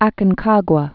(ăkən-kägwə, äkən-)